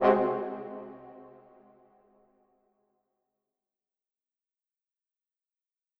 Brass Hits and Stabs
Brass , MIR , Orchestral , Reverb , Section , Single Chord
Staccato
Brass-Mid-Hit-1.wav